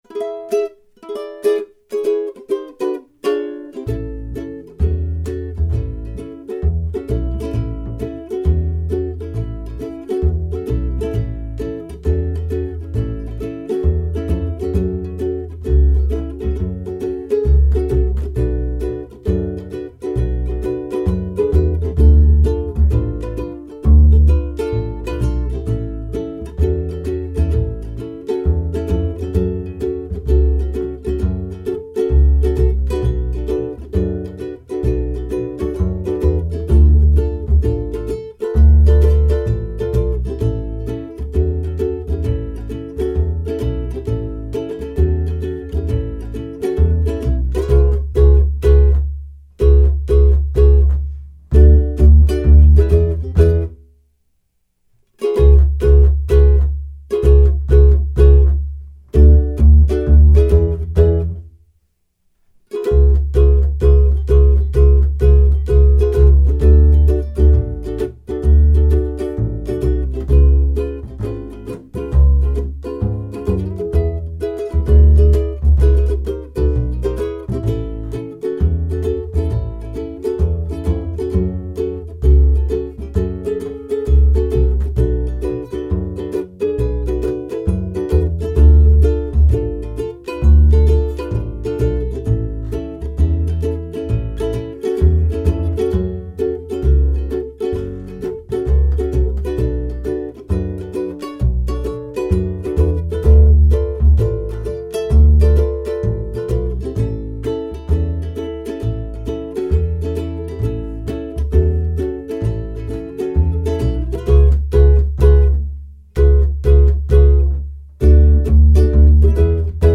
We did record ukulele and bass backing tracks for this